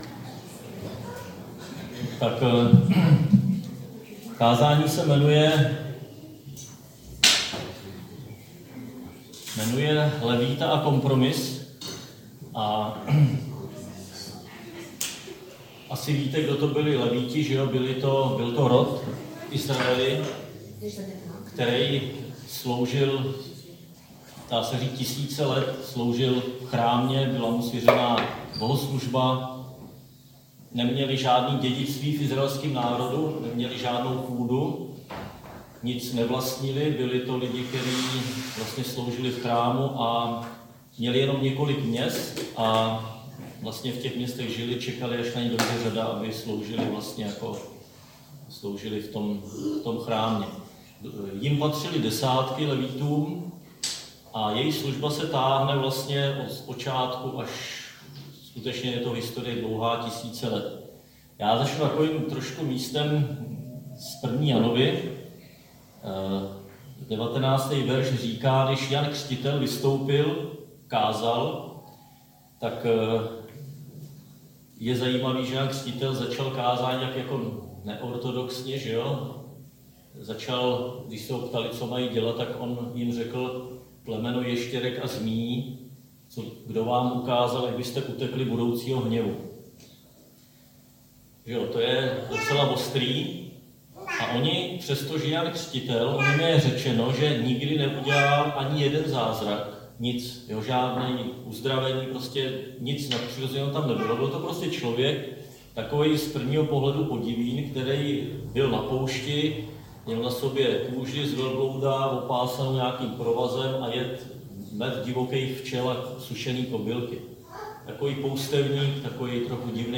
Křesťanské společenství Jičín - Kázání 16.6.2019